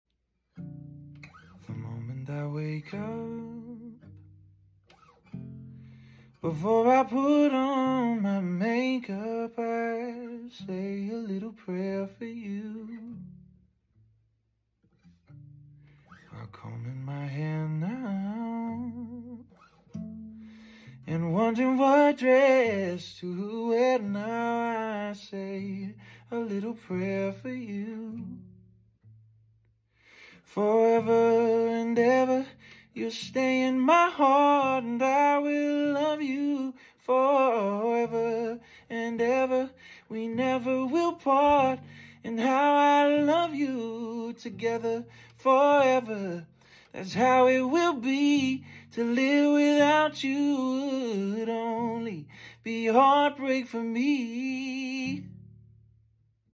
professor singing covers